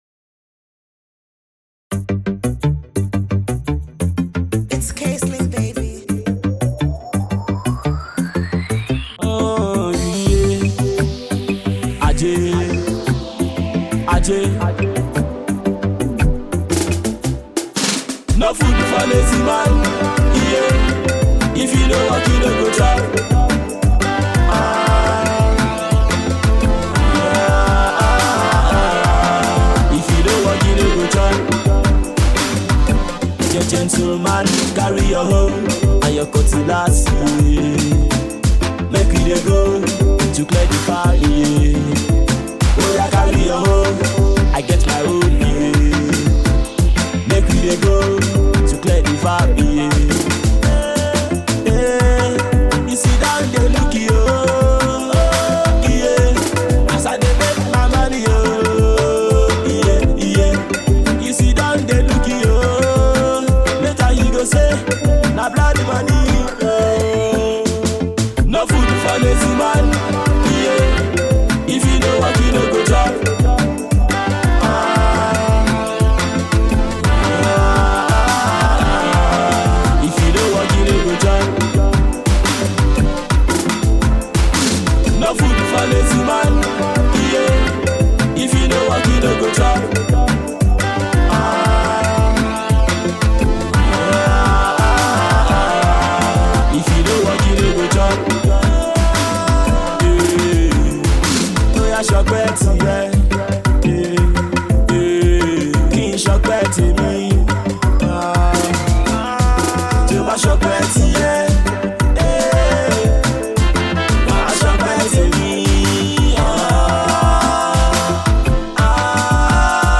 Contemporary Pop
a mid tempo pop song